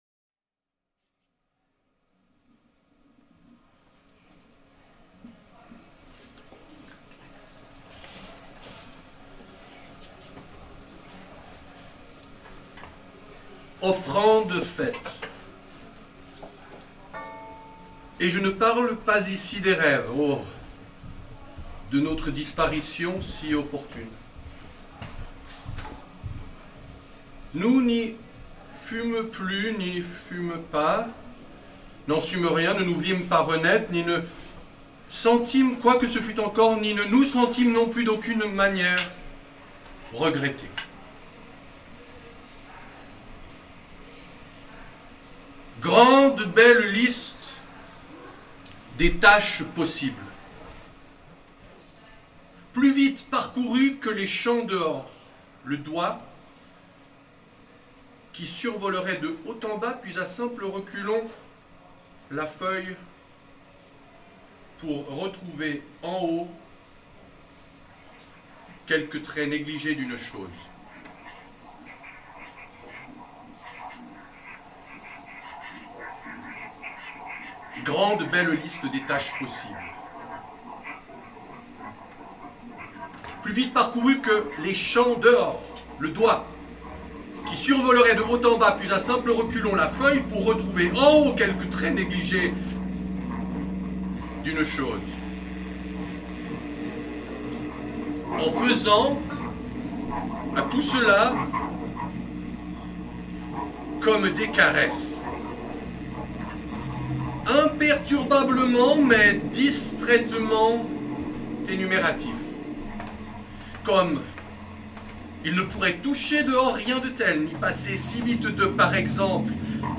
| enregistrement live : 12'21 min |